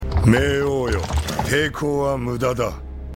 Apparently some people complained about Raoh sounding too soft in the cinematic version.